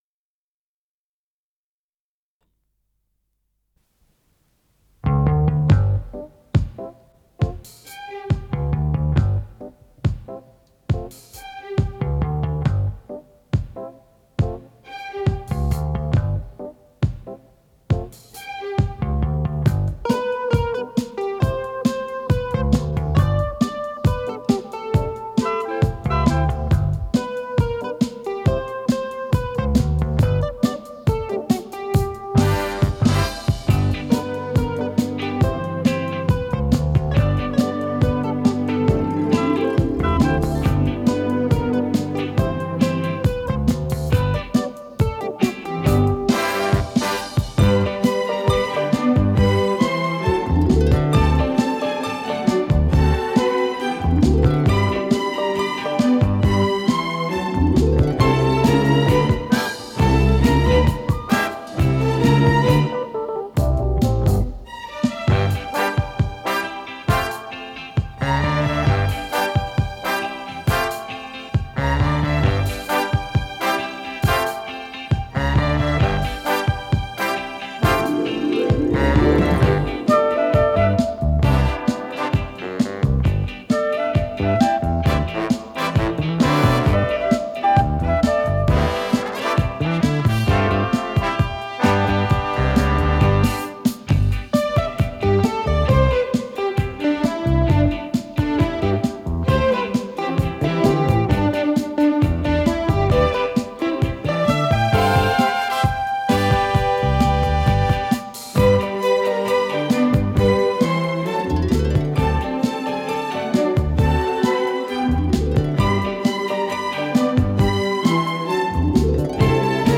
с профессиональной магнитной ленты
ПодзаголовокПьеса для оркестра